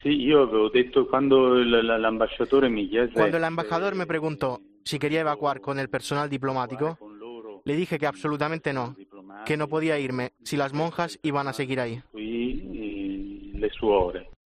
su voz emocionada recuerda su vida allí con muchas limitaciones.